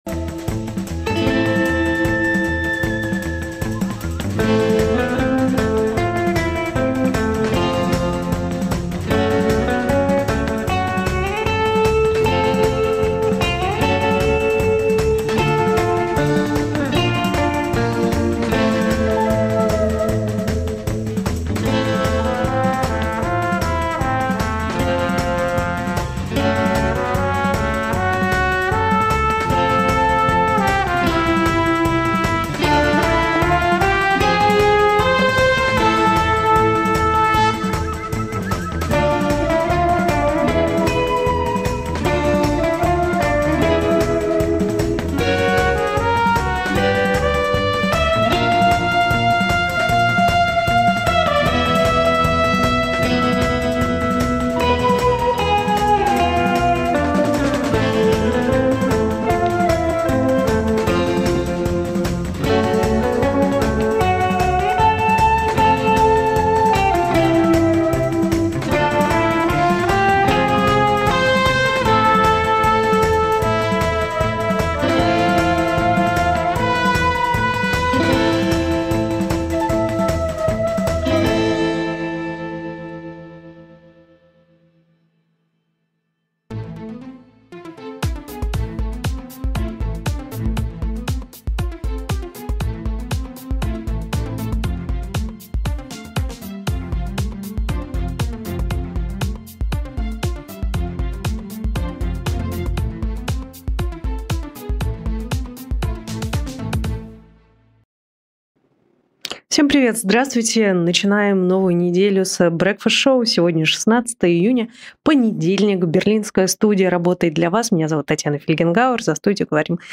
Татьяна Фельгенгауэр обсудит с экспертами в прямом эфире The Breakfast Show все главные новости.